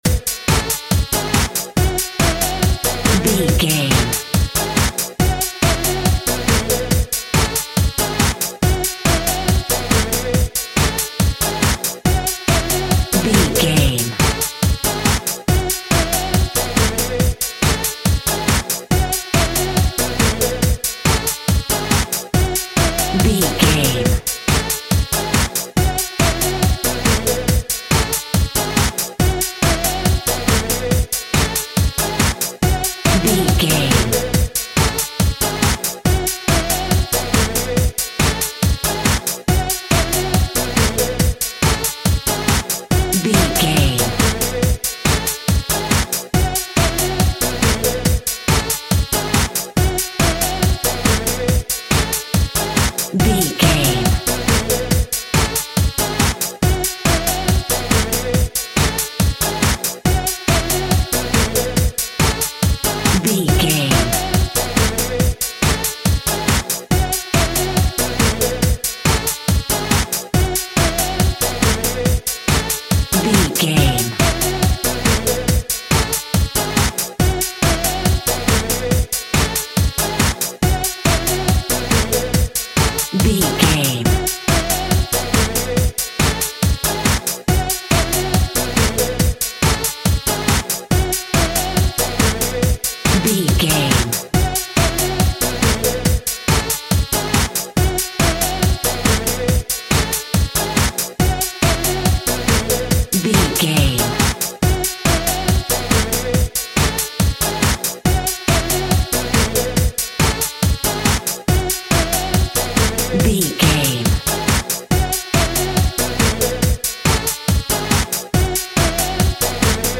Modern House Music.
Fast paced
Aeolian/Minor
F#
Fast
dark
futuristic
groovy
intense
energetic
synthesiser
drum machine
electro house
synth leads
synth bass